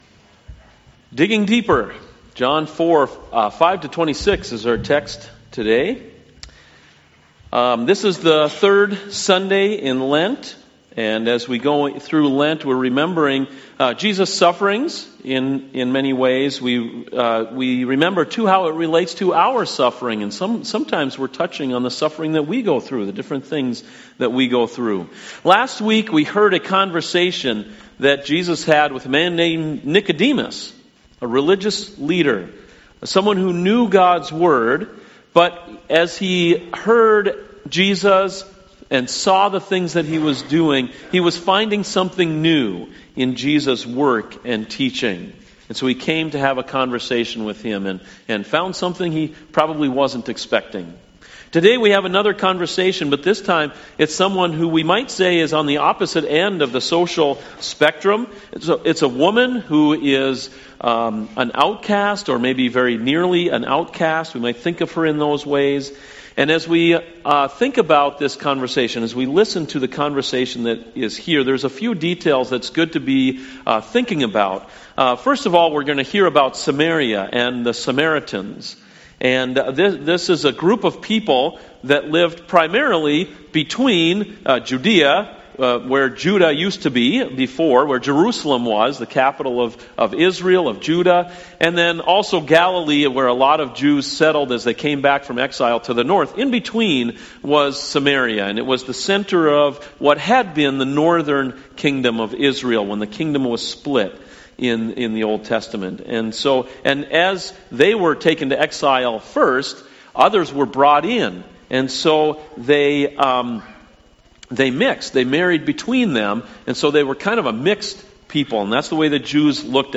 CoJ Sermons